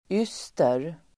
Ladda ner uttalet
Uttal: ['ys:ter]